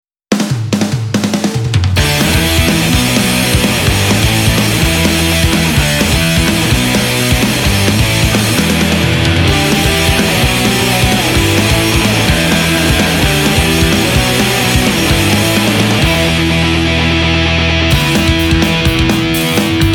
mladé klatovské punkové skupiny